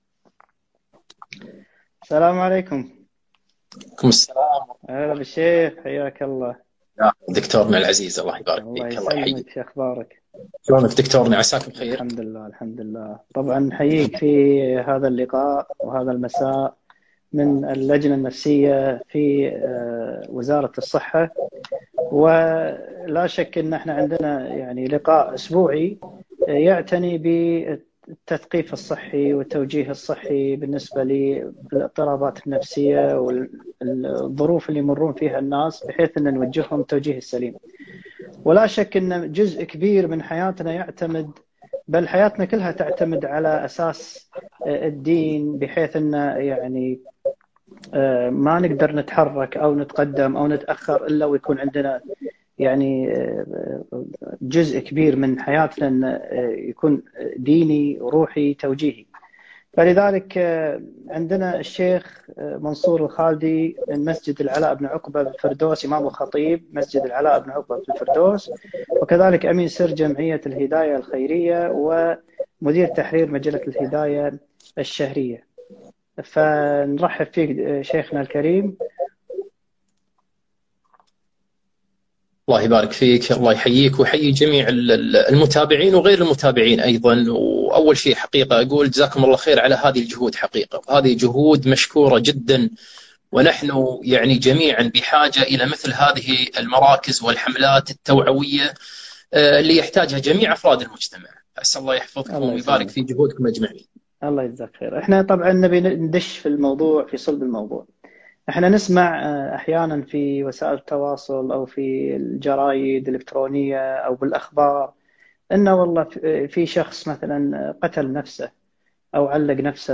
محاضرة - إيذاء النفس ودور الأسرة